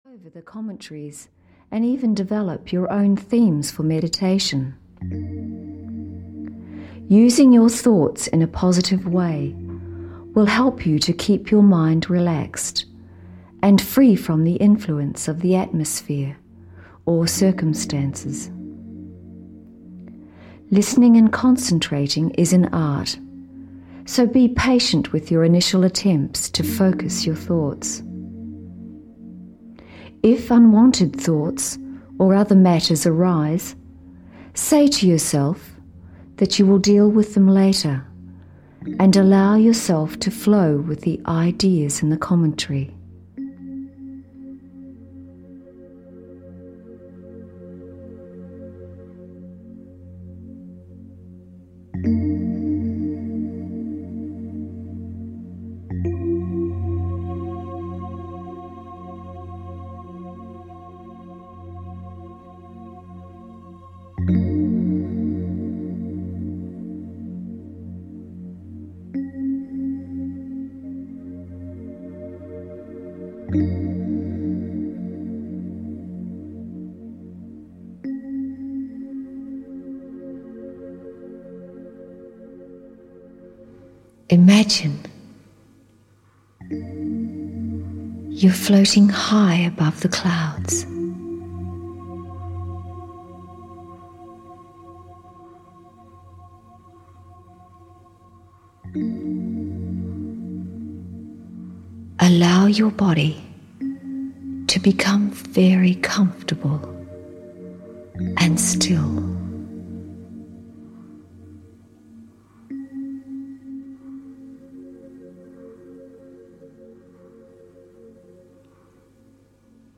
Ukázka z knihy
"Guided Meditation – Time Out" by Brahma Khumaris offers an atmospheric guided meditation that will help you unwind and reconnect with yourself. This guided meditation is invaluable for when you want to take a few minutes out to recharge your batteries or to get creativity and positivity happening in your thoughts.